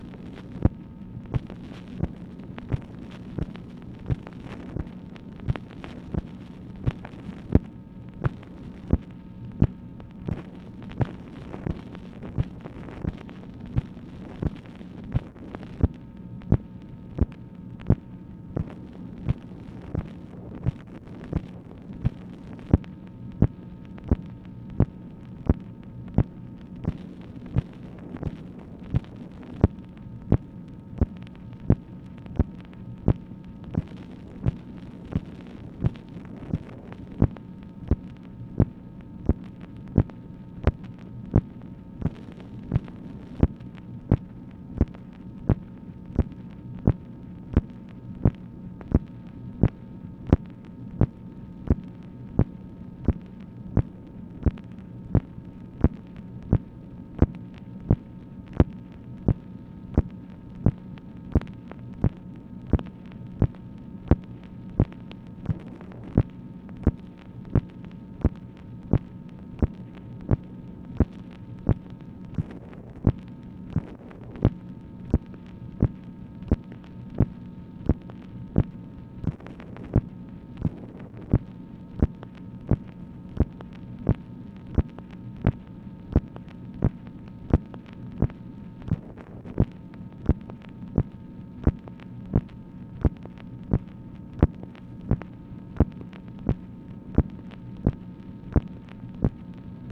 MACHINE NOISE, April 29, 1965
Secret White House Tapes | Lyndon B. Johnson Presidency